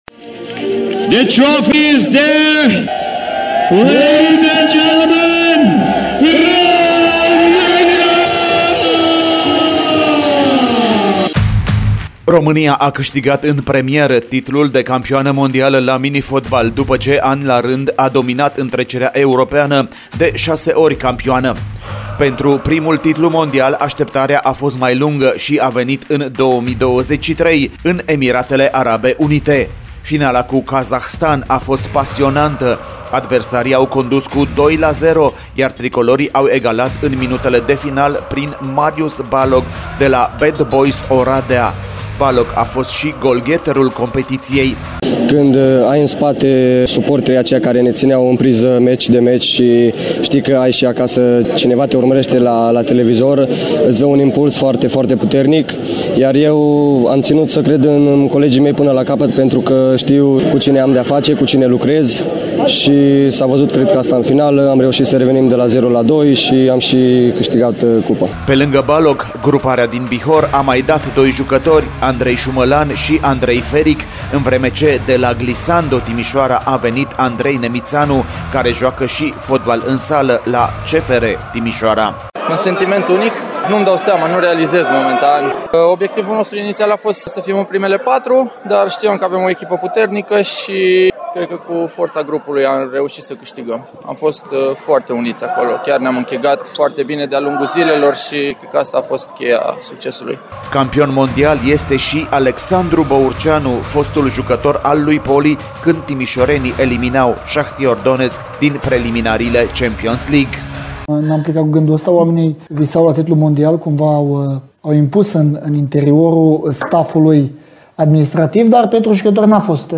reportajul